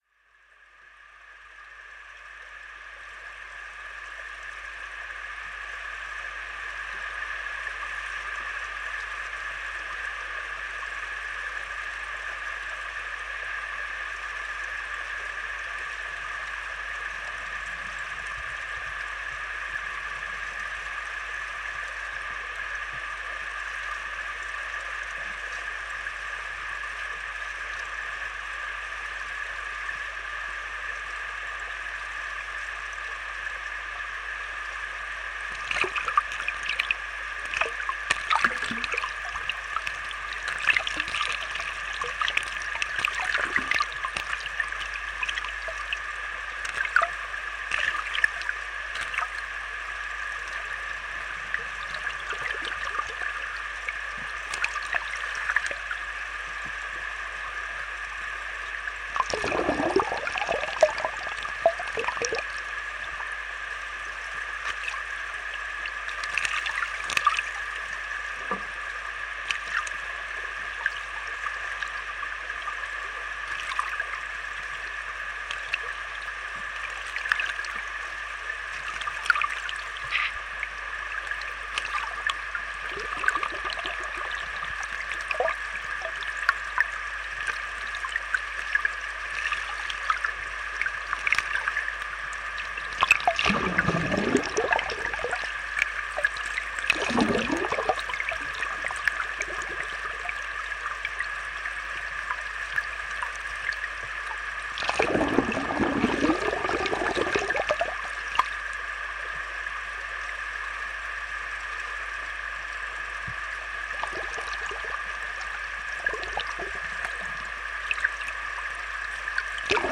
NODAR.00087 – Viseu: Rio Pavia- Improvisação com hidrofones
Gravação de uma improvisação com água, de forma a ser captada por hidrofones. Gravado com Fostex FR-2LE, um pré-amplificador Sonic Devices e dois hidrofones JrF.
Tipo de Prática: Arte Sonora
Viseu-Rio-Pavia-Improvisação-com-hidrofones.mp3